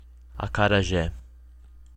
Akara (Yoruba: àkàrà; Portuguese: acarajé, pronounced [akaɾaˈʒɛ]